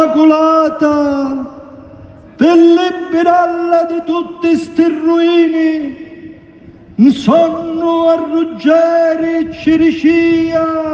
LE PROVE DELLO SPETTACOLO